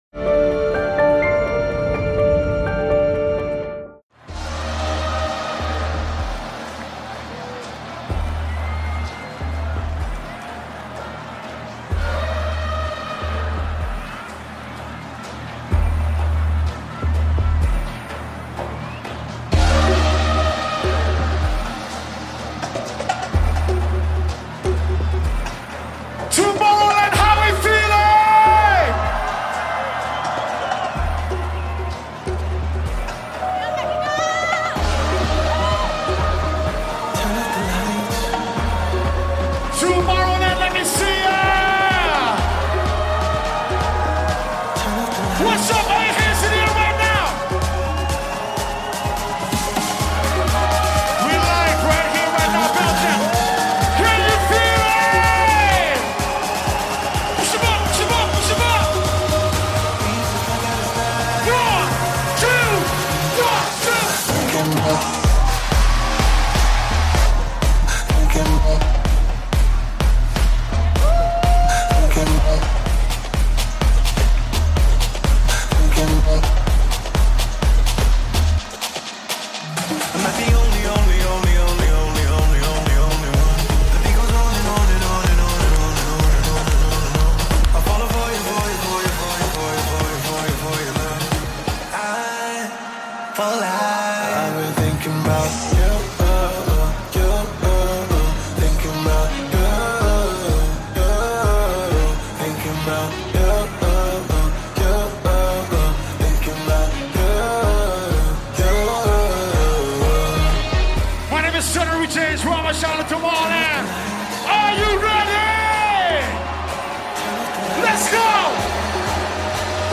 Also find other EDM Livesets, DJ